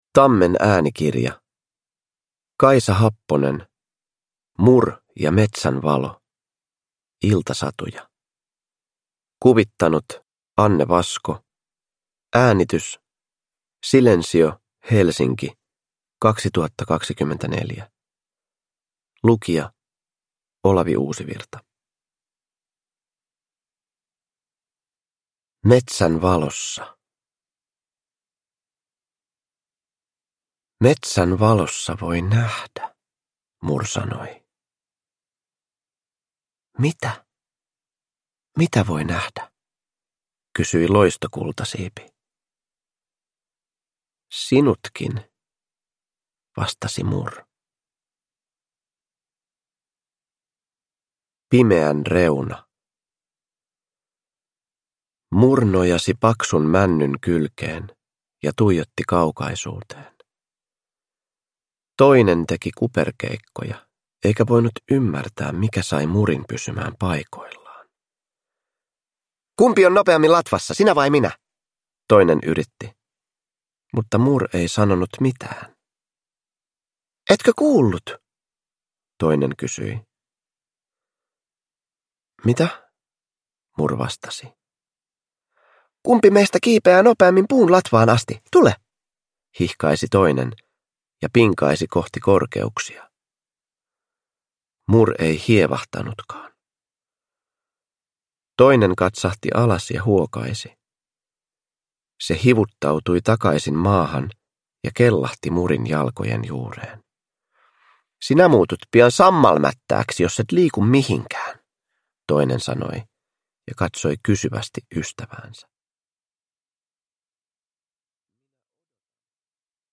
Mur ja metsän valo – Ljudbok
Uppläsare: Olavi Uusivirta